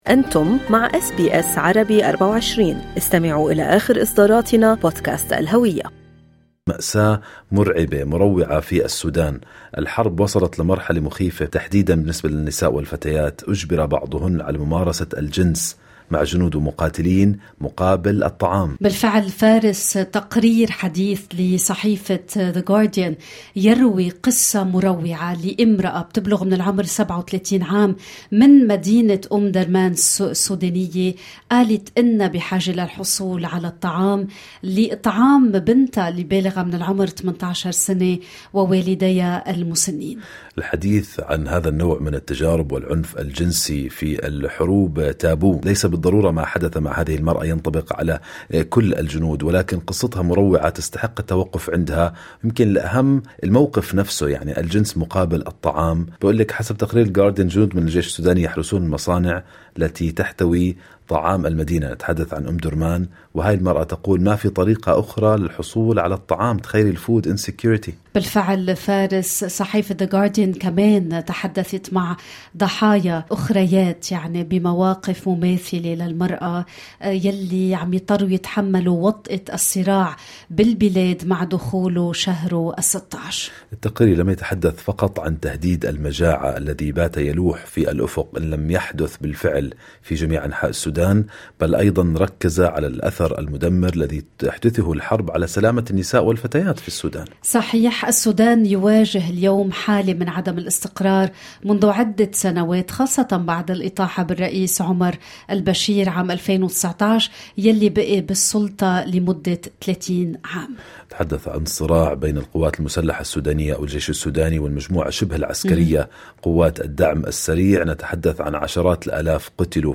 "جنس مقابل الطعام": ناشطة سودانية أسترالية تروي قصصاً من الواقع "المروع" في السودان